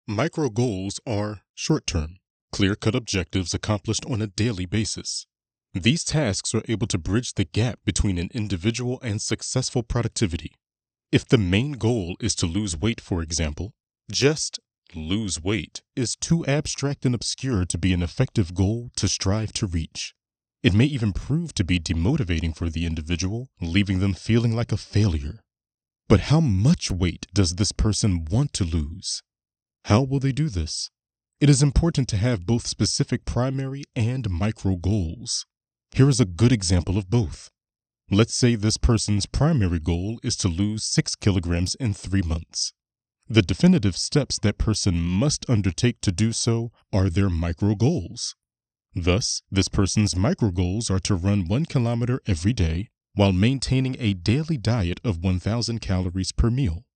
Audiobook Samples
Informative, educational
Micro-Goals_Audiobook-Demo_With-Noise-Reduction.mp3